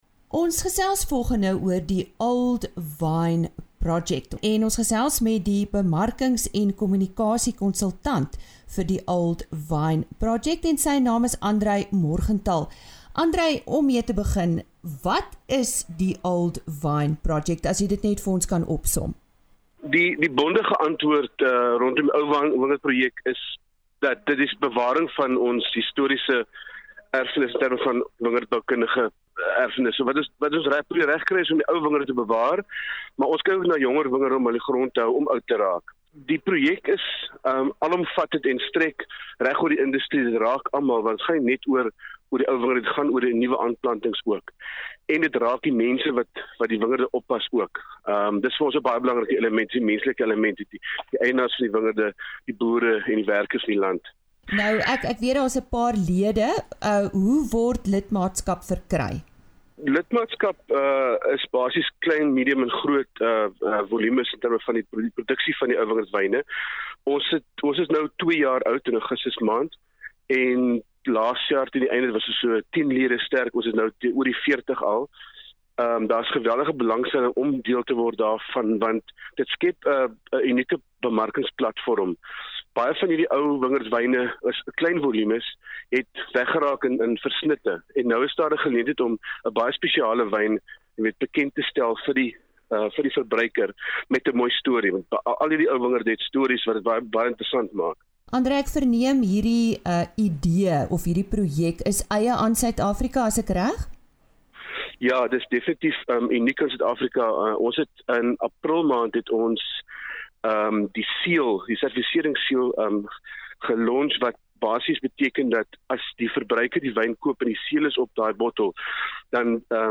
RSG Landbou onderhoud: 19 September 2018 - Old Vine Project